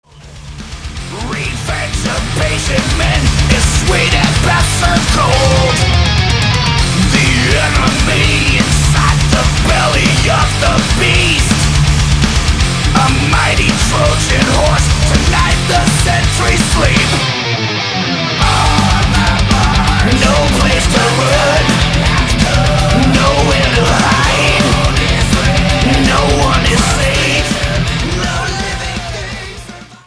Studio: Lattitude South Studios, Leiper's Fork, Tennessee
Genre: Thrash Metal, Heavy Metal